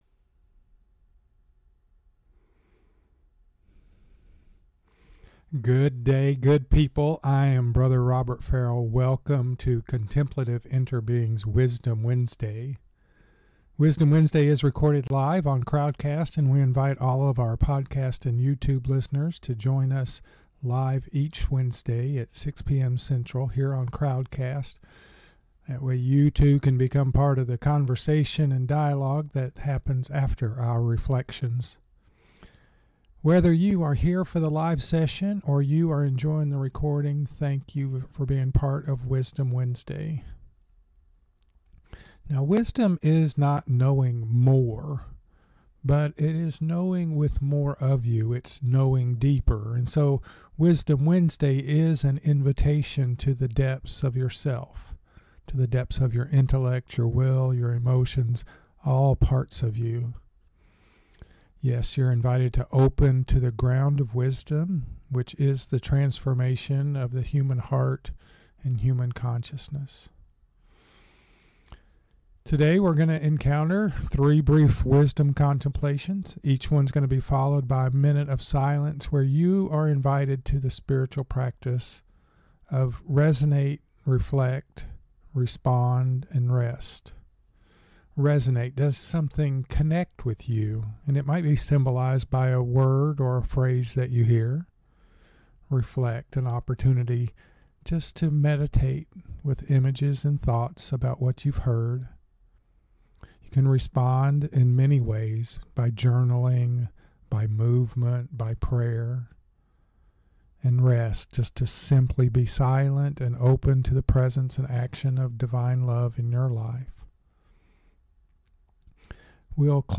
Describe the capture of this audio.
Wisdom Wednesday is recorded live on Crowdcast.